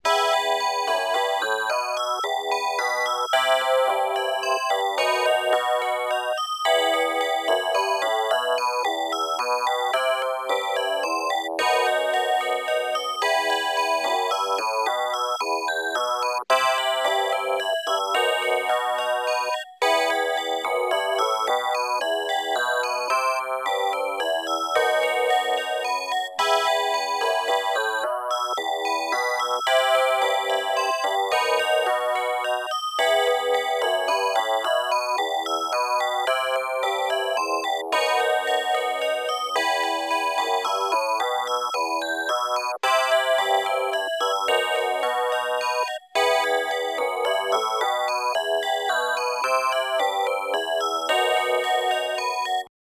aastra-5370-melody-9.mp3